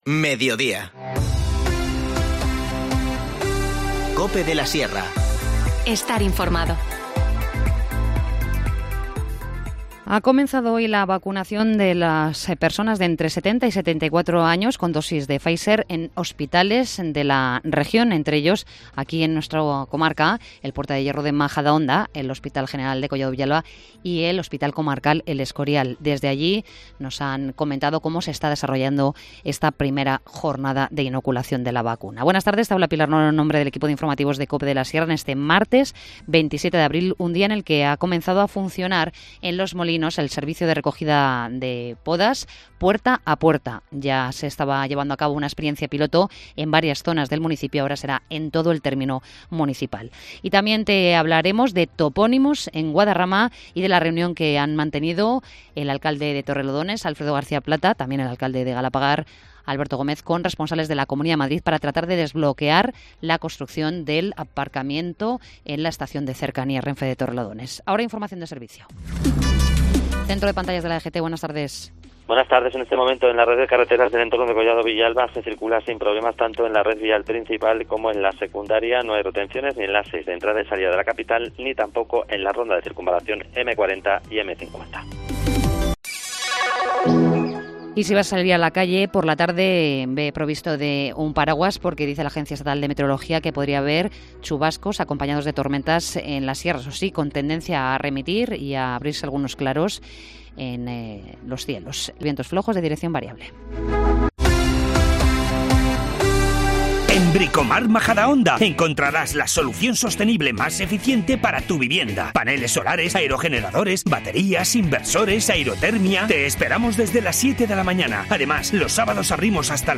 Informativo Mediodía 27 abril